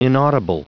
Prononciation du mot inaudible en anglais (fichier audio)
Prononciation du mot : inaudible